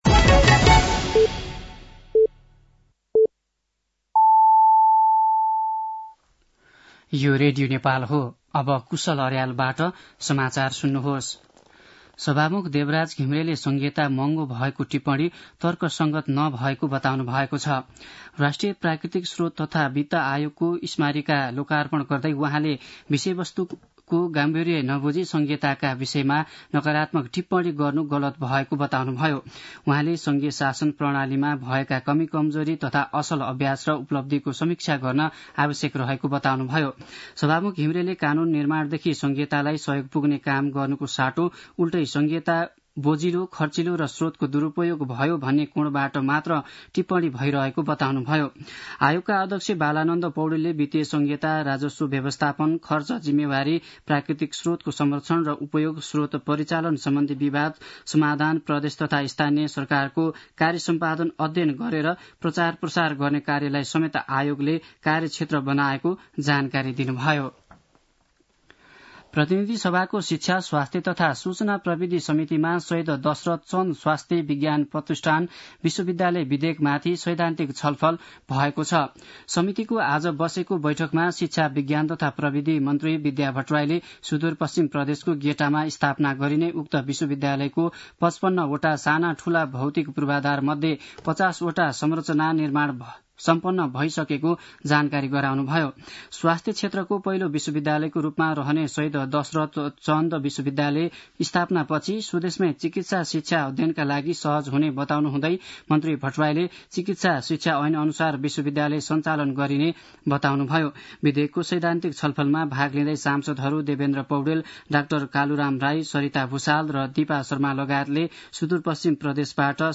साँझ ५ बजेको नेपाली समाचार : ६ पुष , २०८१
5-pm-nepali-news-9-05.mp3